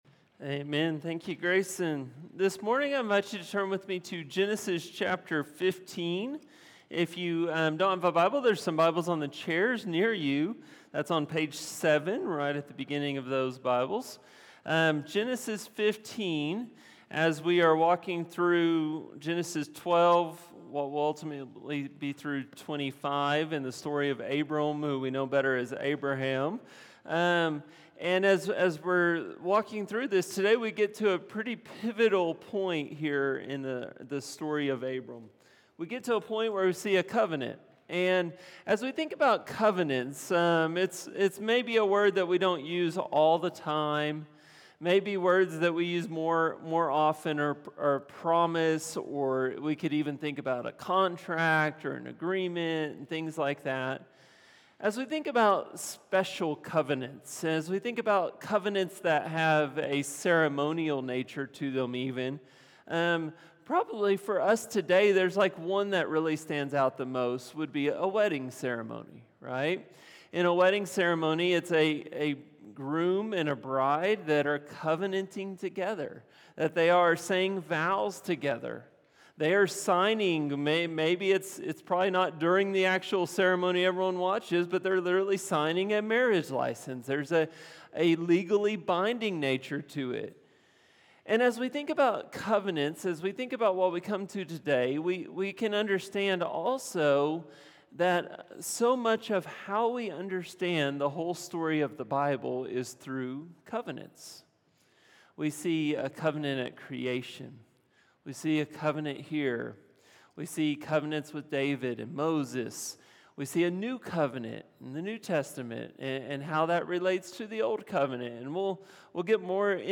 A message from the series "Genesis 12-25."